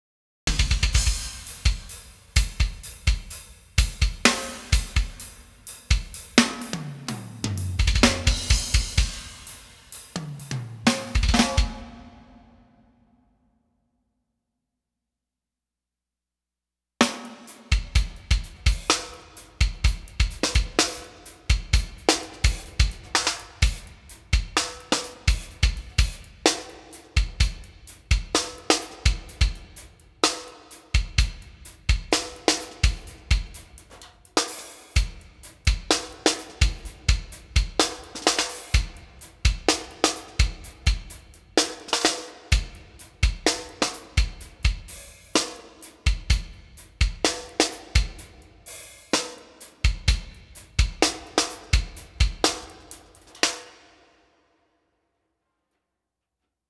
For this comparison I mixed a quick ITB drum mix. Then sent the individual drum channels (16) to the FOLCROM/One of the listed makeup gain amplifiers.
LEVR-no-Transformer.wav